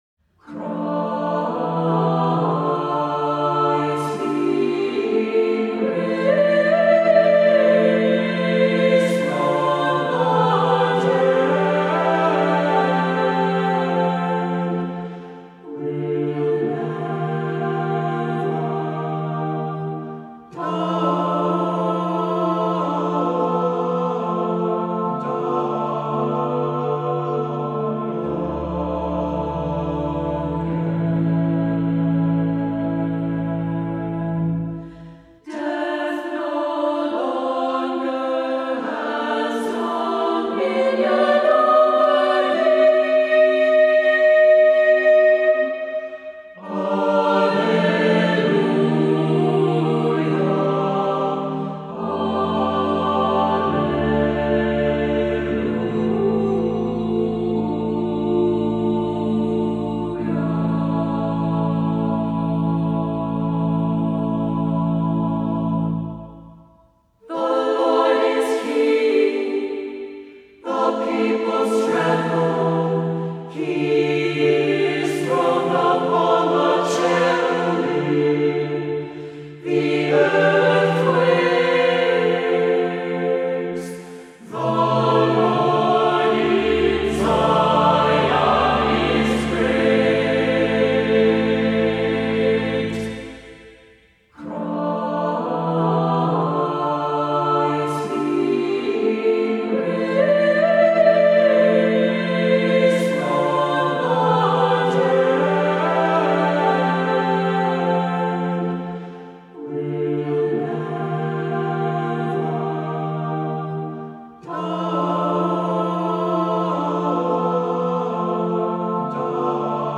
Voicing: SATB, a cappella